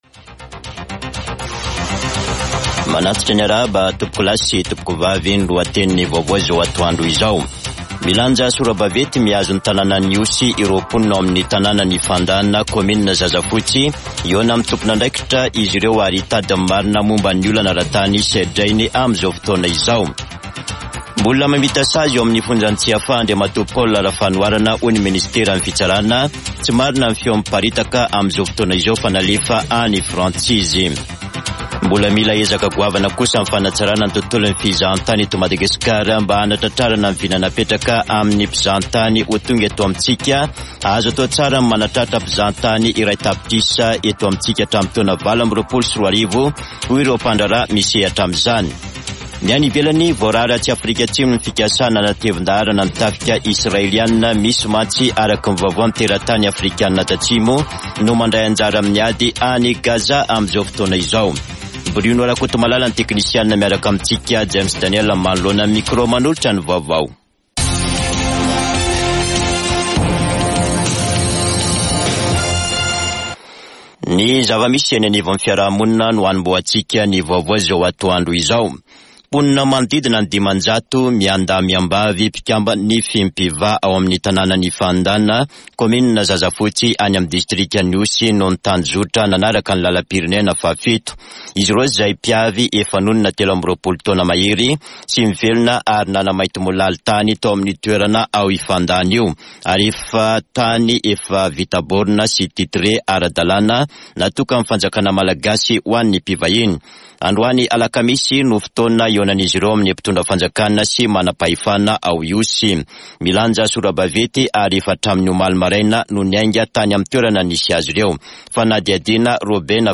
[Vaovao antoandro] Alakamisy 21 desambra 2023